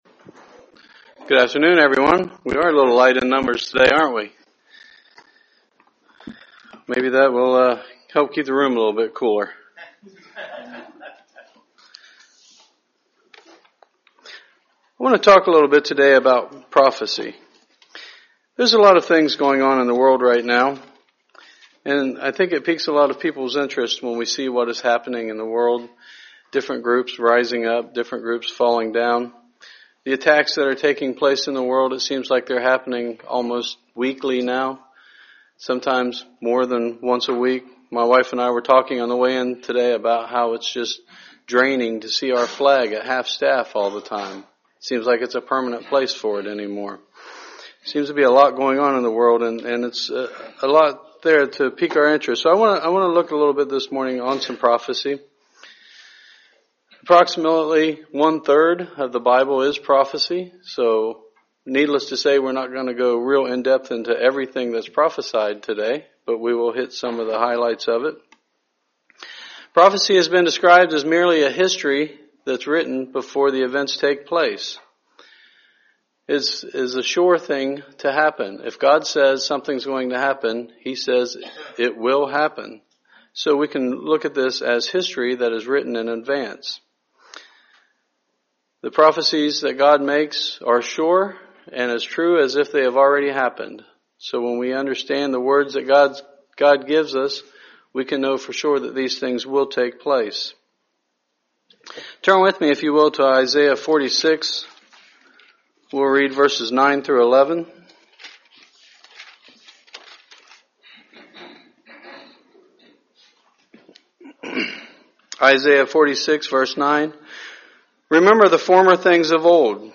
This sermon goes over the Prophecies of Matthew 24 and the Seals of Revelation.